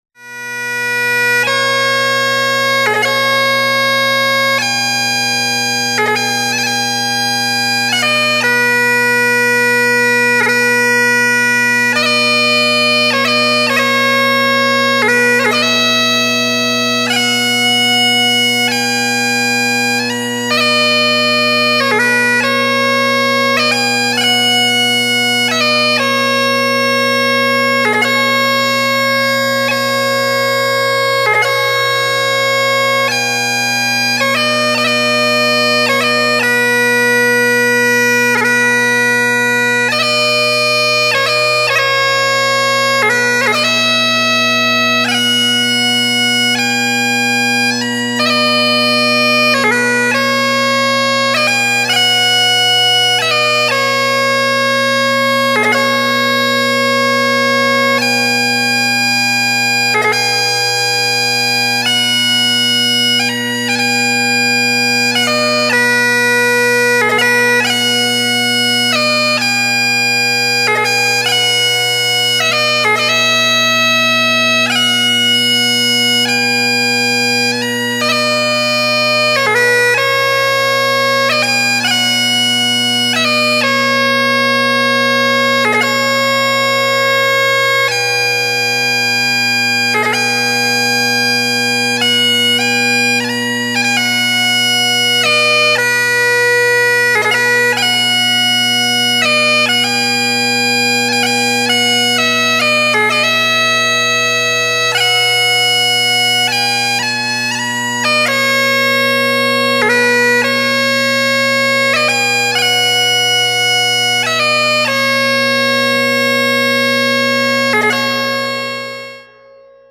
• This is another old Gaelic song with a nice melody.